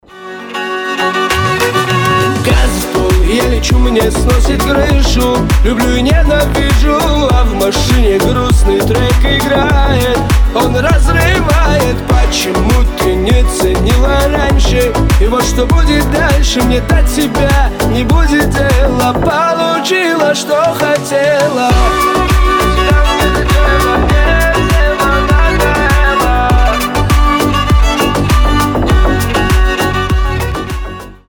• Качество: 320, Stereo
мужской голос
скрипка